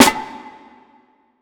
TC SNARE 24.wav